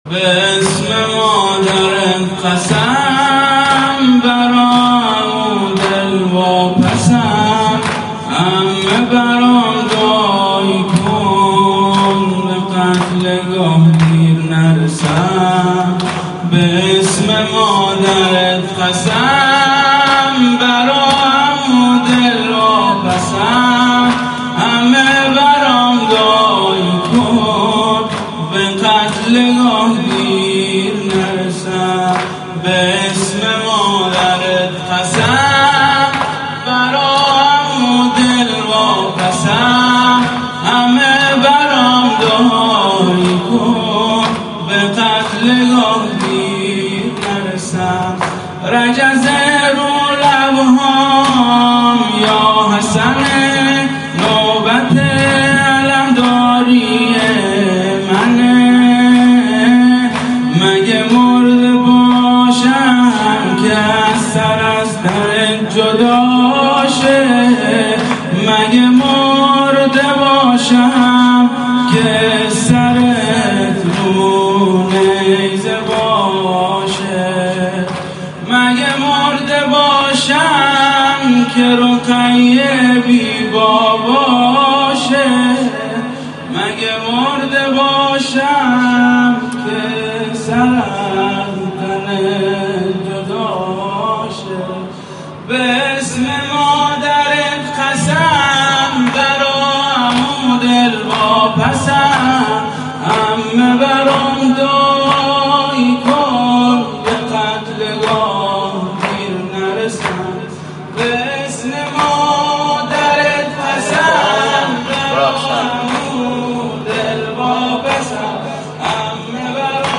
زمینه/به اسم مادرت قسم/شب پنجم محرم 1442 هیئت ناصر الحسین